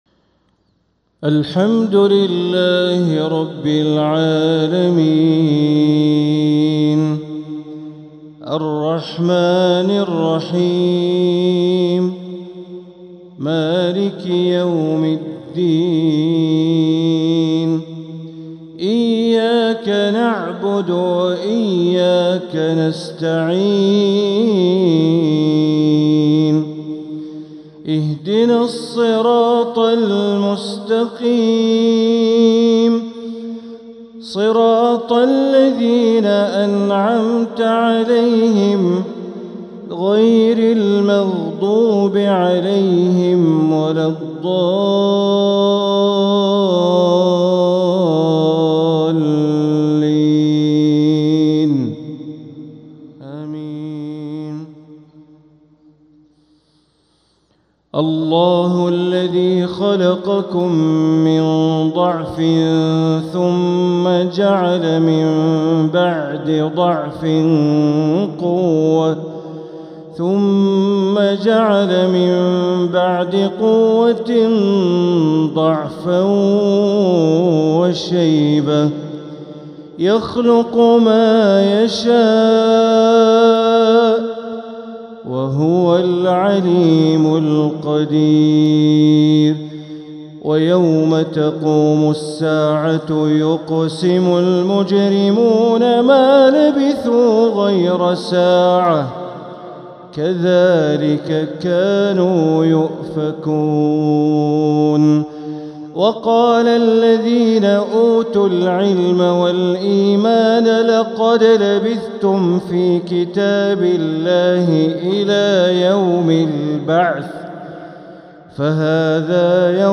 تلاوة من سورة الروم مغرب السبت ٣-١-١٤٤٧ > 1447هـ > الفروض - تلاوات بندر بليلة